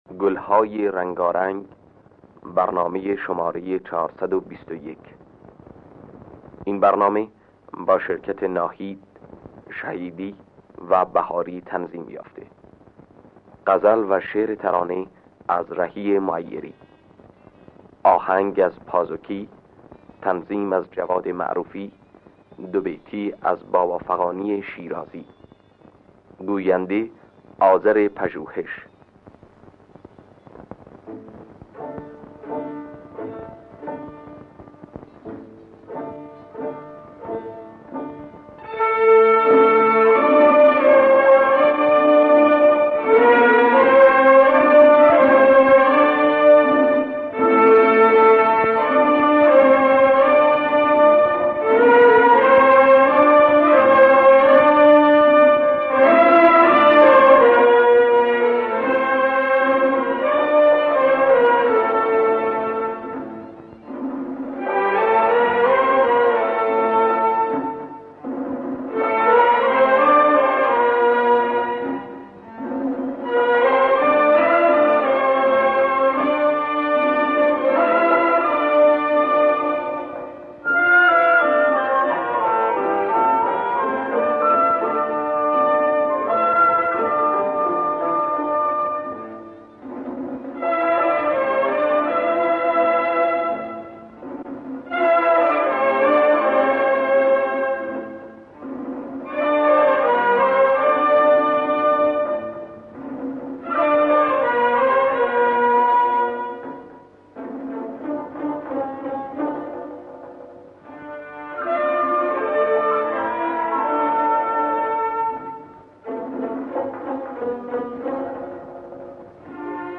گلهای رنگارنگ ۴۲۱ - سه‌گاه
خوانندگان: ناهید دایی‌جواد عبدالوهاب شهیدی نوازندگان: اصغر بهاری جواد معروفی